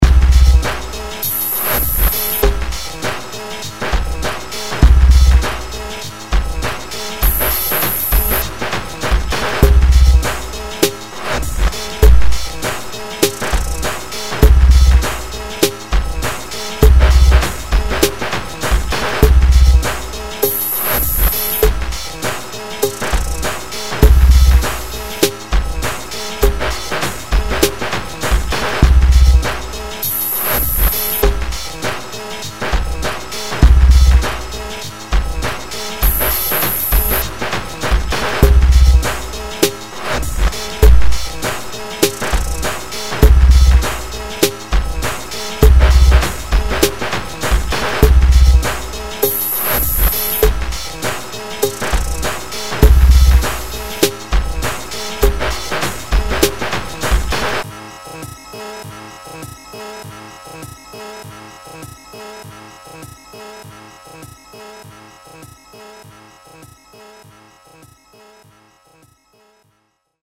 Guitares, samples, claviers, programmations, bricolages.
à évolué vers un son plus électronique